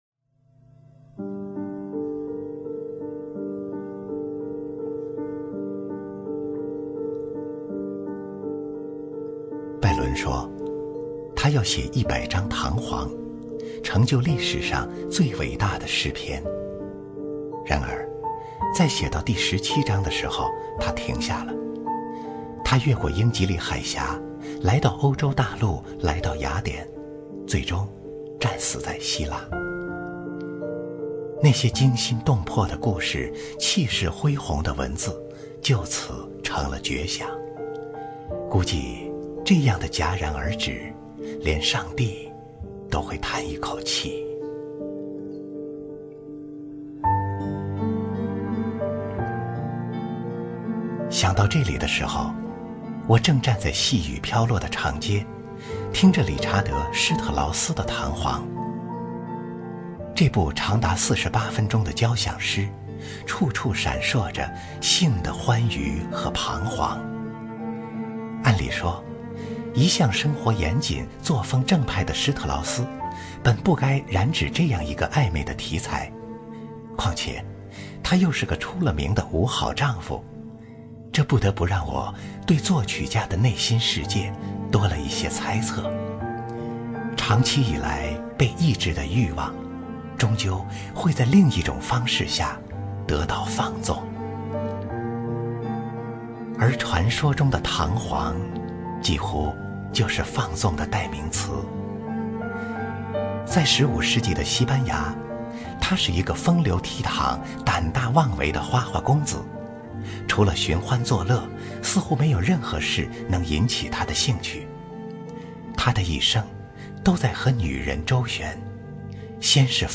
经典朗诵欣赏